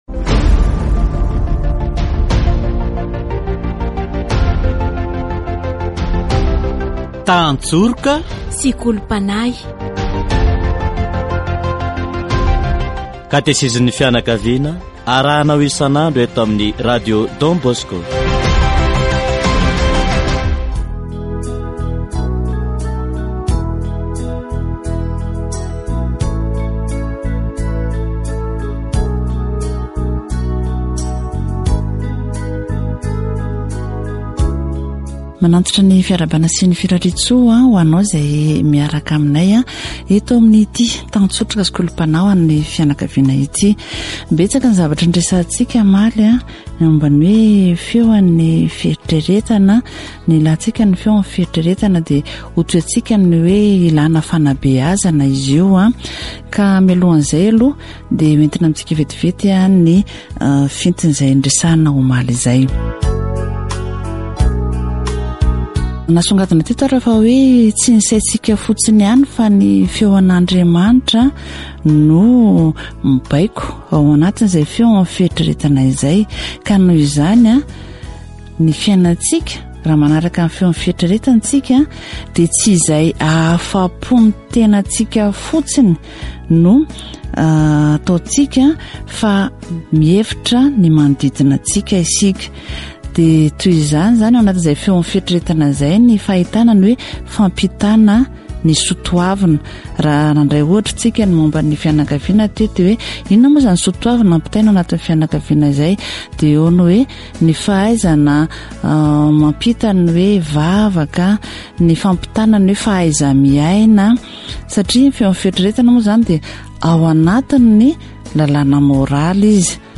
The self-examination is carried out with an impartial heart and mind. Catechesis on Conscience